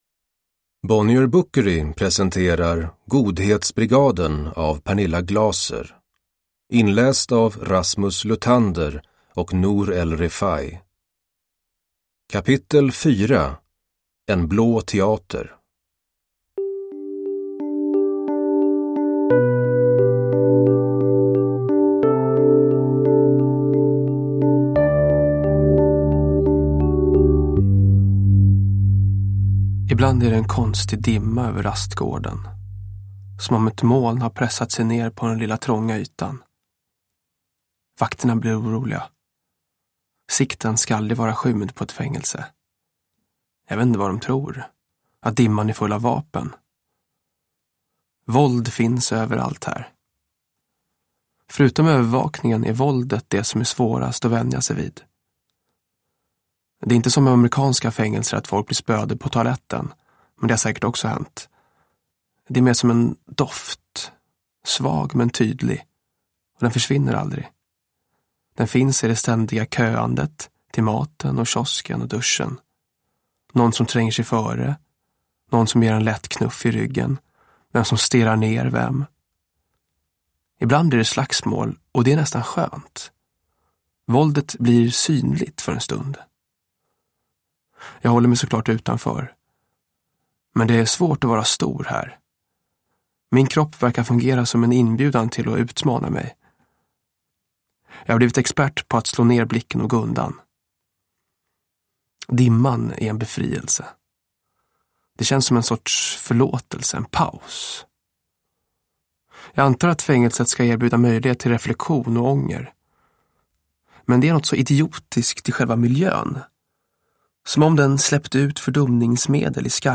Godhetsbrigaden. S1E4, En blå teater – Ljudbok – Laddas ner